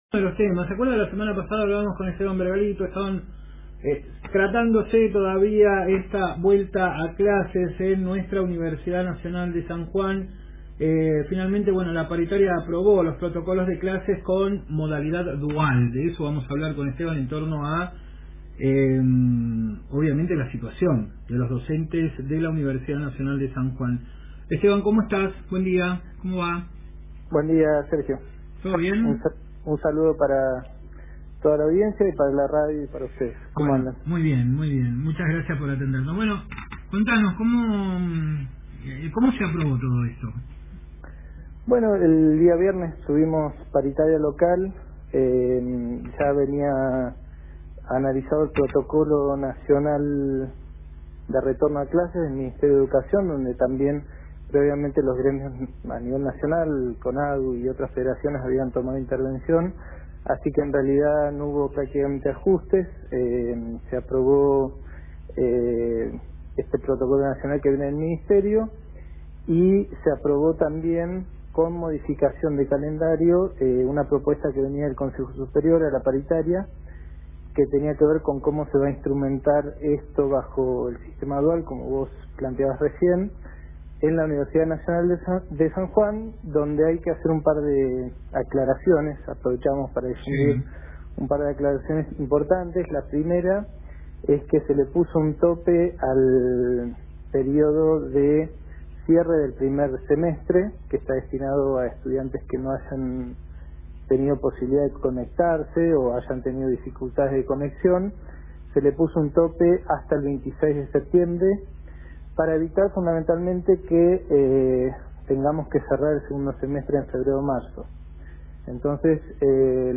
habló en Radio Universidad FM 93.1